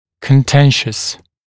[kən’tenʃəs][кэн’тэншэс]дискуссионный, спорный